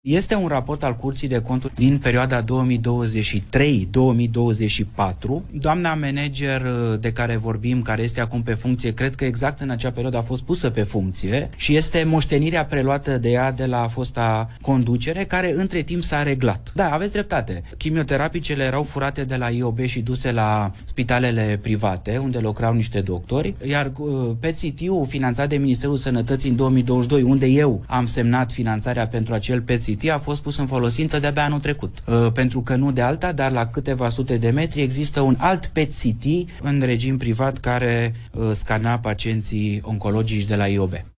Ministrul demisionar Alexandru Rogobete: „Chimioterapicele erau furate de la IOB și duse la spitale private”